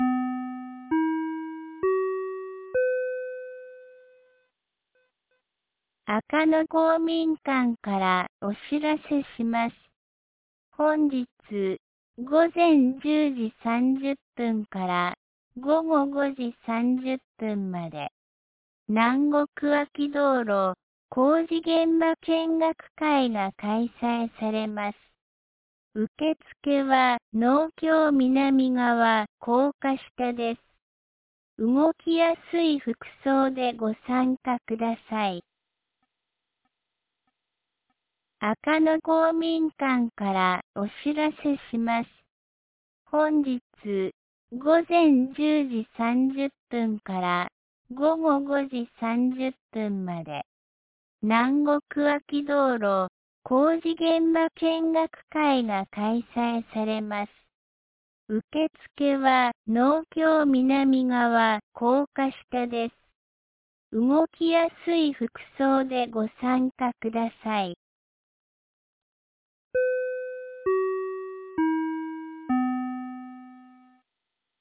2025年04月18日 12時56分に、安芸市より赤野へ放送がありました。